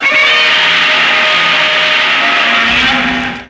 File:Godzilla The Series Roar.mp3
Godzilla_The_Series_Roar.mp3